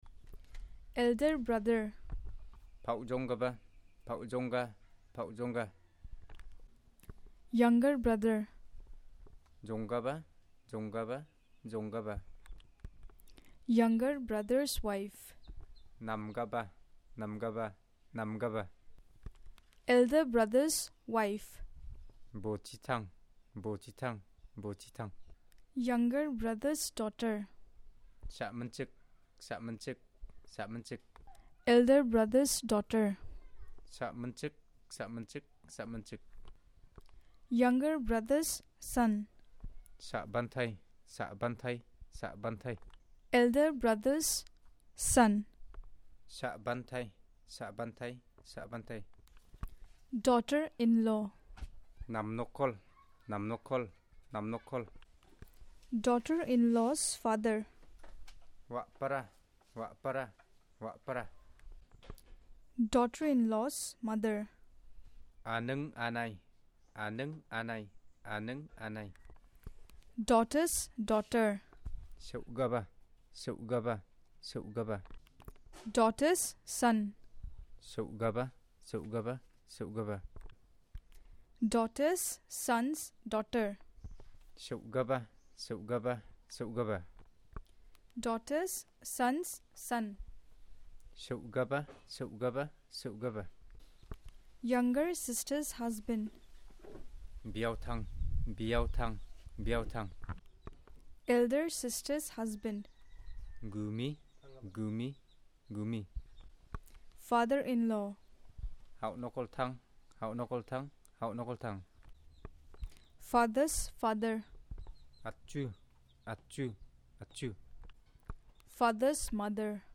Elicitation of words about Kinship terms